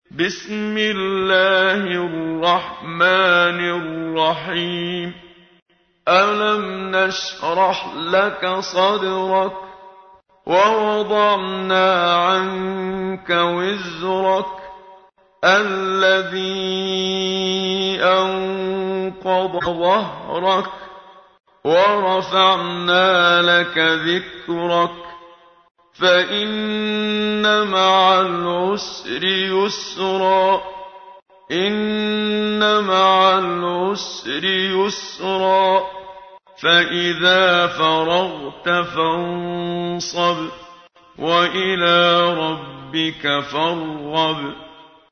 تحميل : 94. سورة الشرح / القارئ محمد صديق المنشاوي / القرآن الكريم / موقع يا حسين